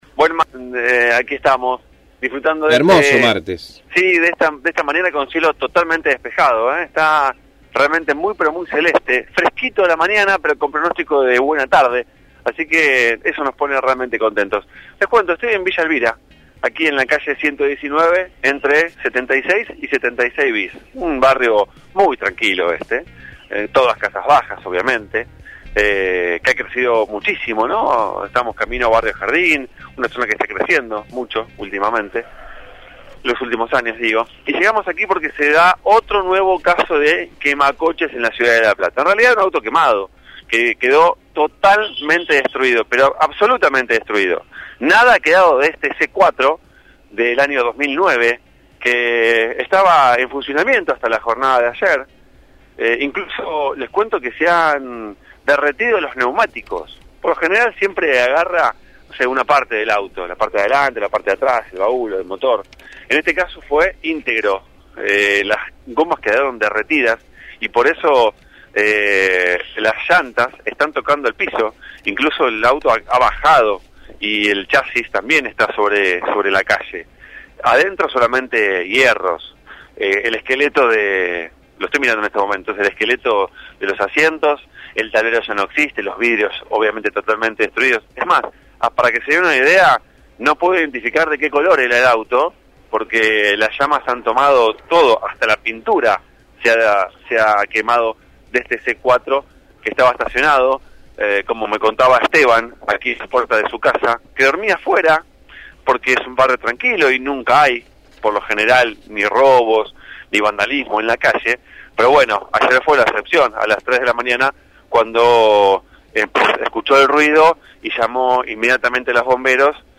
MÓVIL/ Nuevo ataque de «quemacoches» en La Plata